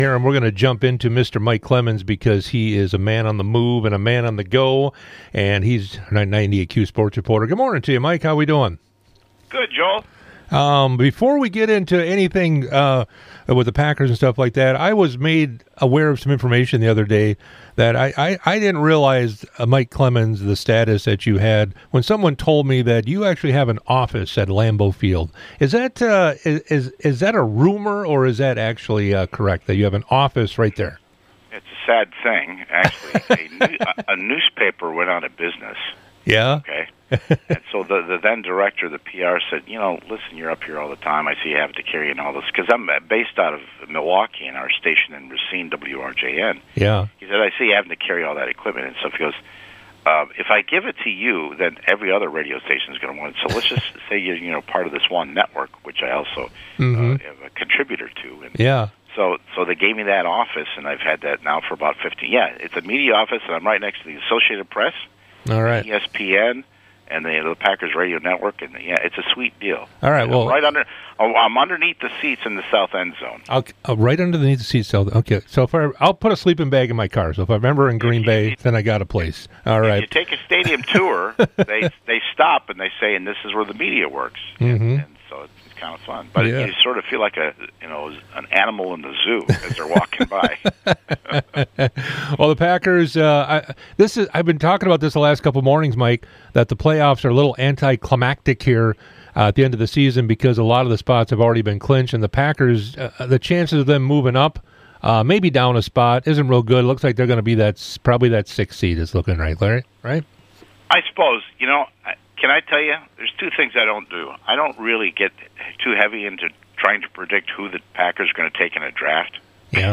Interviews and special broadcasts from 98Q Country in Park Falls.
98q interviews